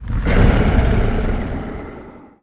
death.ogg